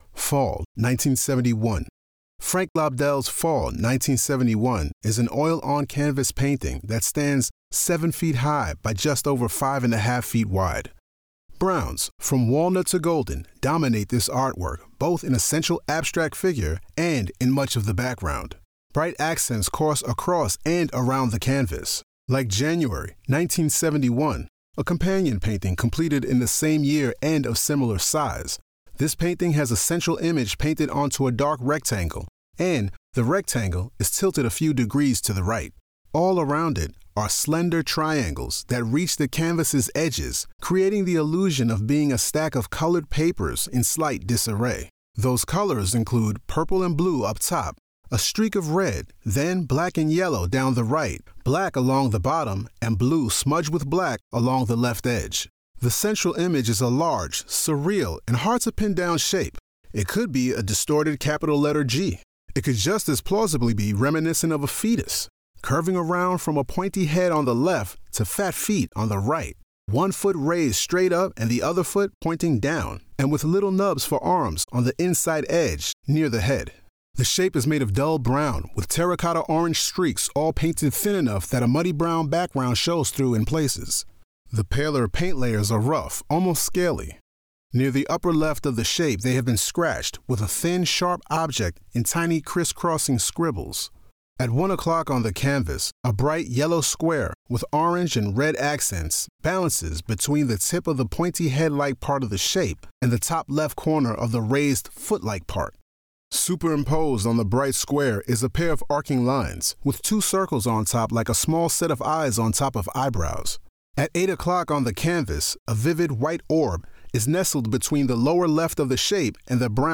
Audio Description (02:57)